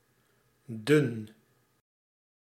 Ääntäminen
IPA: /dʏn/